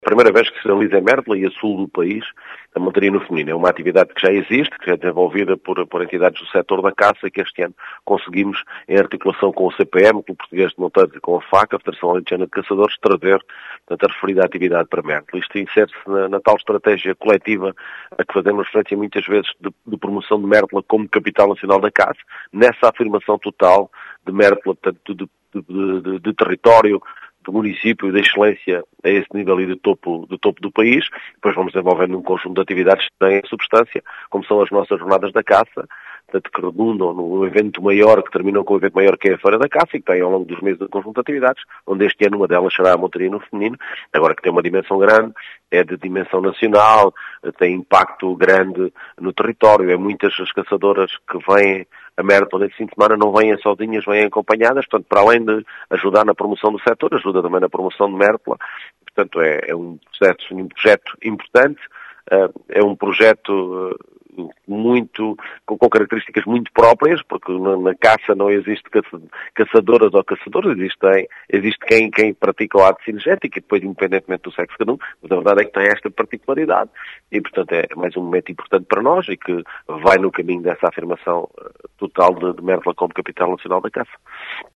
As explicações são do presidente da Câmara de Mértola, Mário Tomé, que fala de uma iniciativa de “dimensão nacional”.